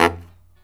LOHITSAX13-R.wav